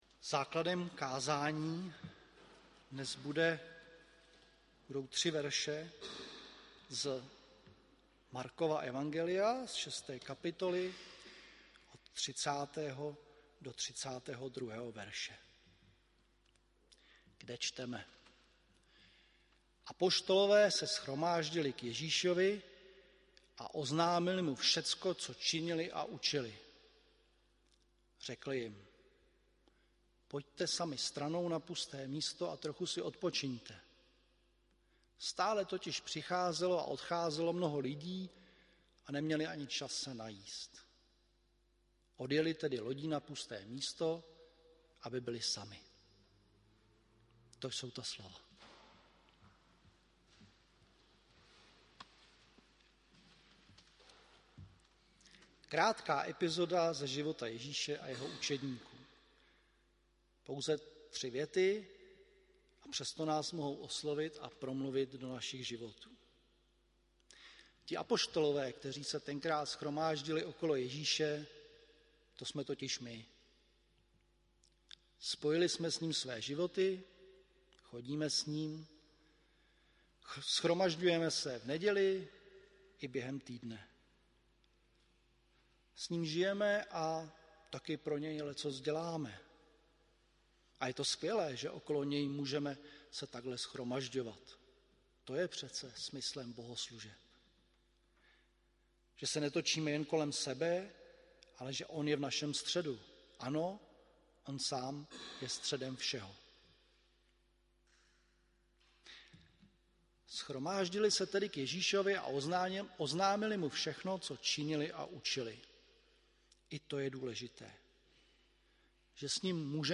Druhá neděle po sv. Trojici 26. června 2022 AD
Bohoslužby vedl a čteným kázáním na text z Markova evangelia,
audio kázání